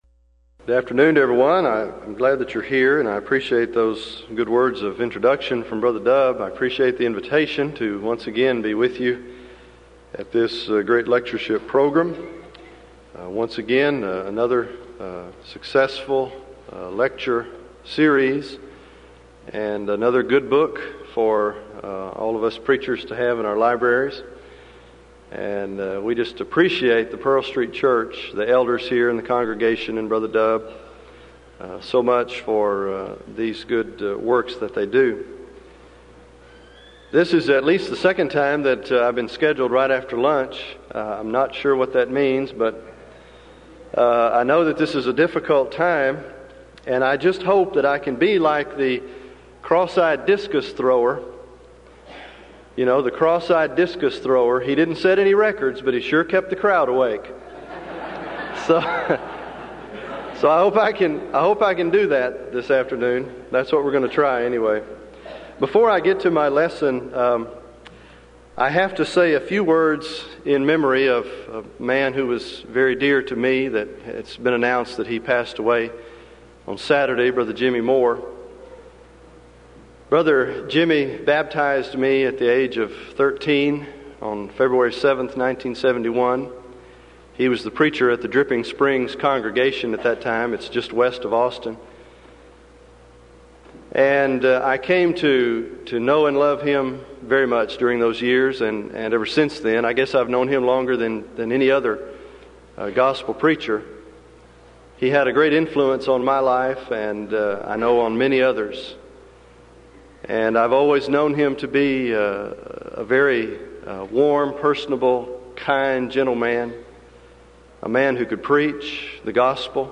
Event: 1994 Denton Lectures Theme/Title: Studies In Joshua, Judges And Ruth